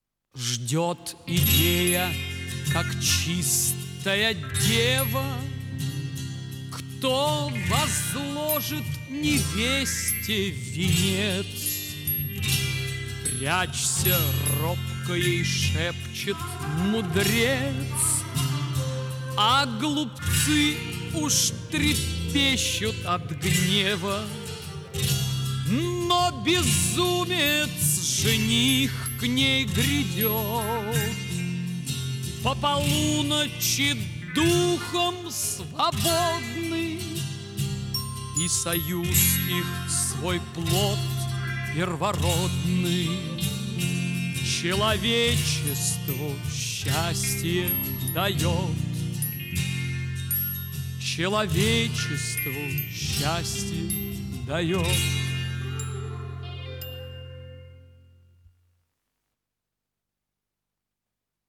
Жанр: Rock, Pop
Стиль: Art Rock, Ballad, Vocal, Classic Rock
Вокальная сюита